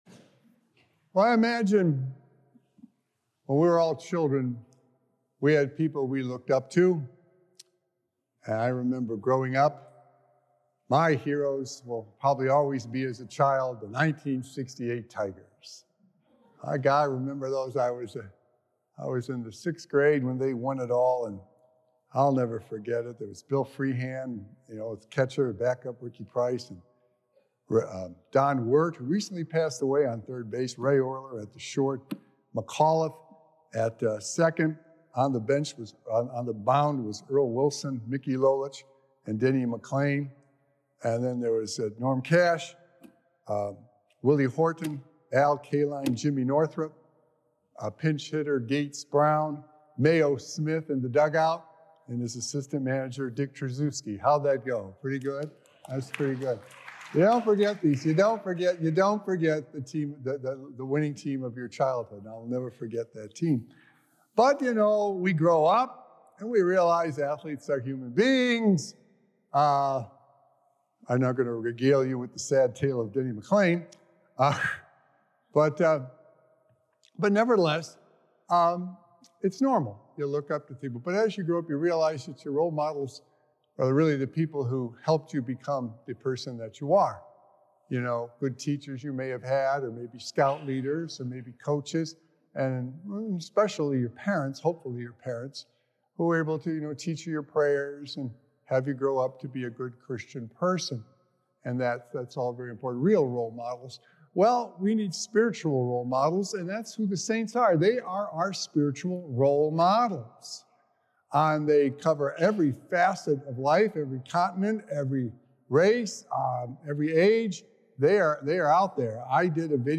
Sacred Echoes - Weekly Homilies Revealed
Recorded Live on Friday, November 1st, 2024 at St. Malachy Catholic Church.